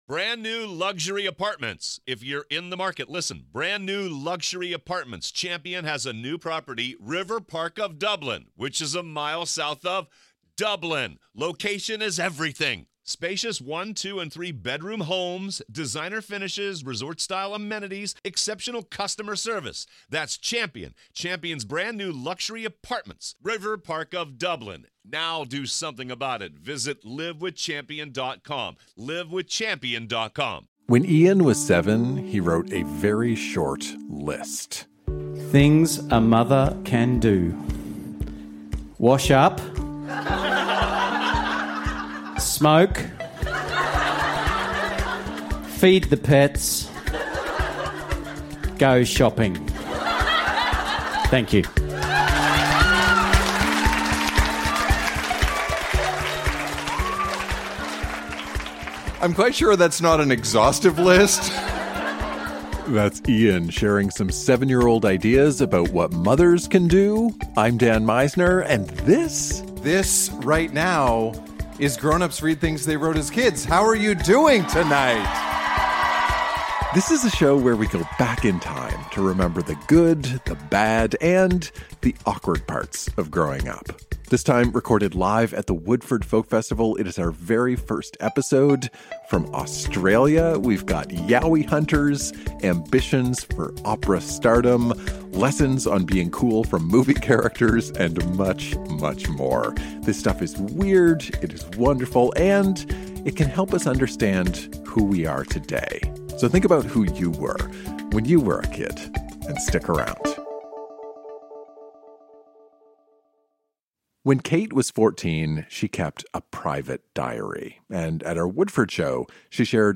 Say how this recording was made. Recorded live at the Woodford Folk Festival.